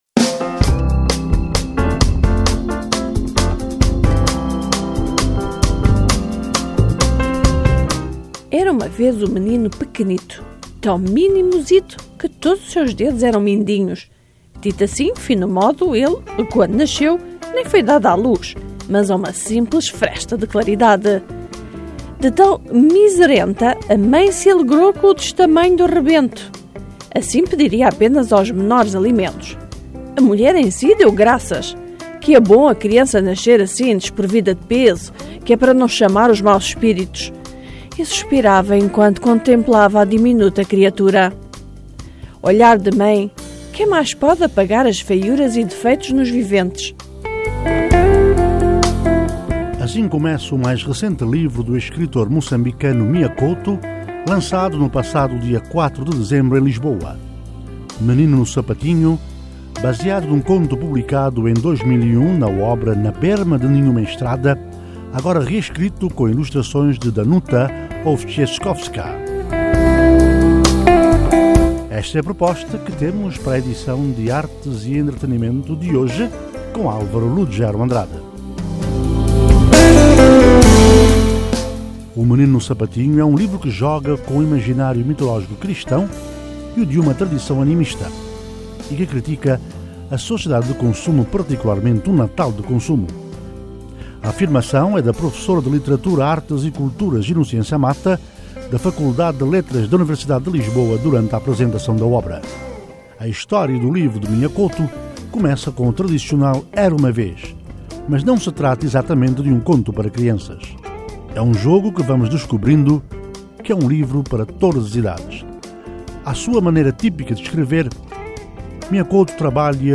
Mia Couto fala o seu novo livro - 10:50